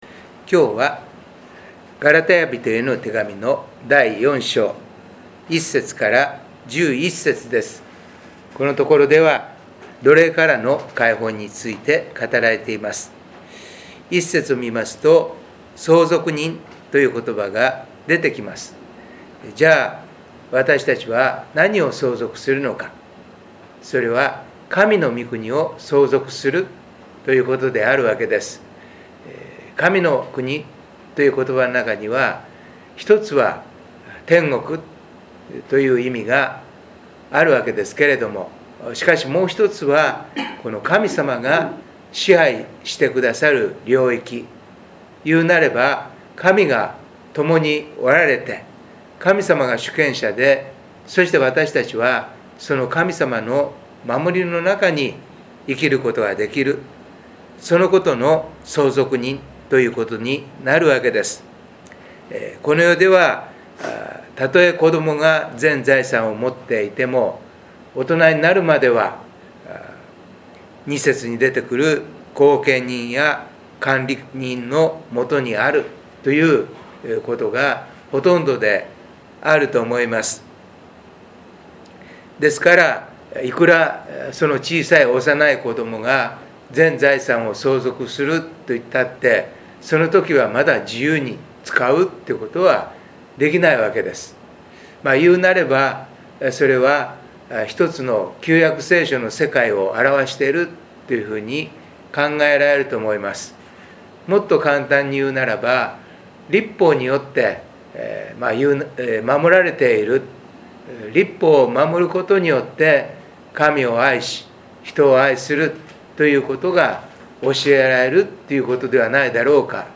メッセージ概要 最近、コロナ感染も少し収まってきている様子ですが、ニュースを見ると原因不明の子どもの急性肝炎や「サル痘」など初めて聞く病気の感染が出てきます。 身体の健康も大切ですが、心の健康も大事ではないでしょうか。